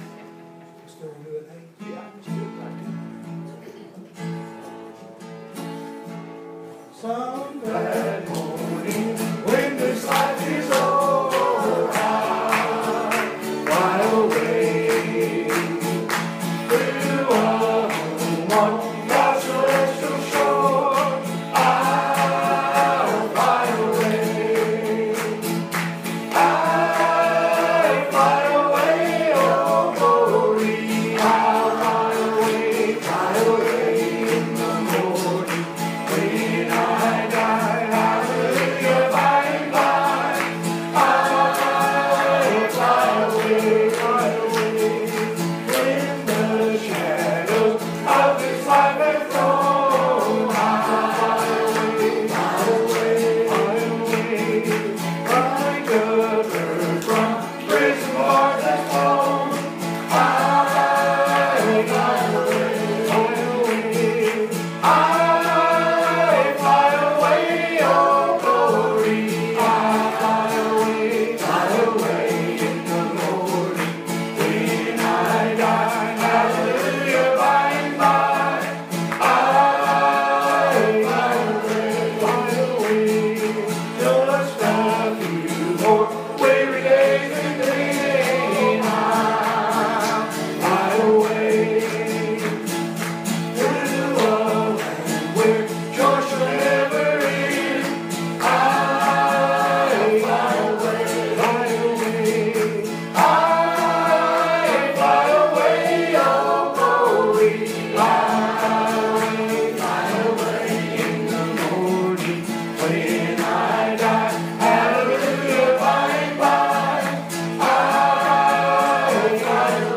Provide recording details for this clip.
Sunday July 16 2017 Services at Riverview